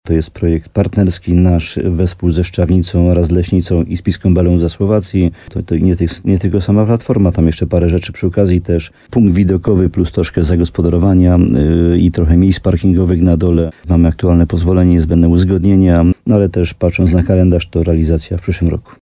Równolegle, w ramach innego projektu Gmina Łącko złożyła wniosek o dofinansowanie budowy punktu widokowego na Górze Zyndrama – mówi wójt Jan Dziedzina.